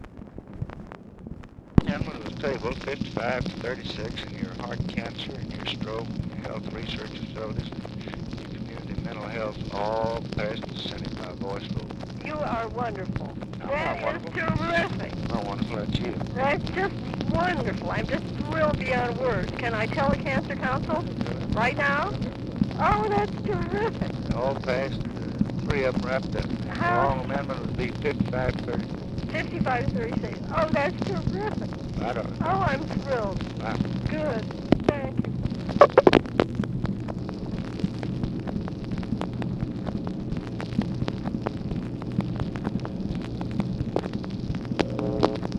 Conversation with MARY LASKER, June 28, 1965
Secret White House Tapes